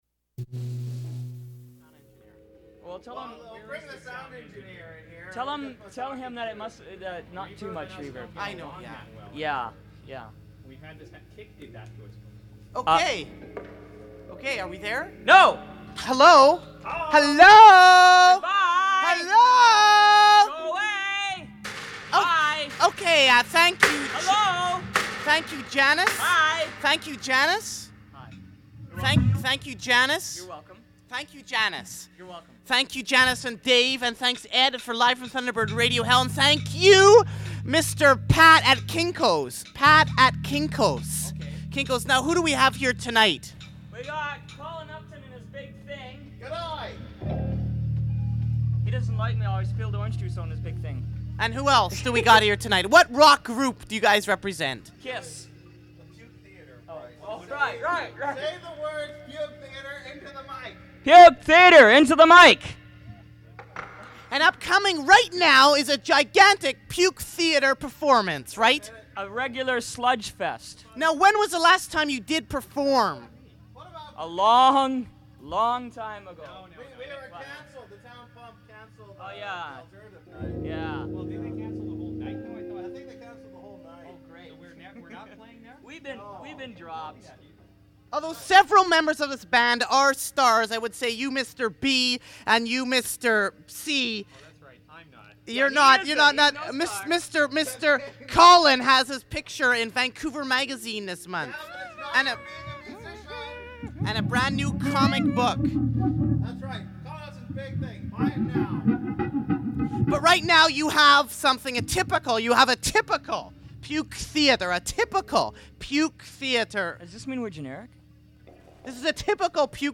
Recording of a live performance
Vancouver-based musical group
including introductions by Nardwuar the Human Serviette.